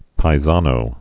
(pī-zänō) also pai·san (-zän)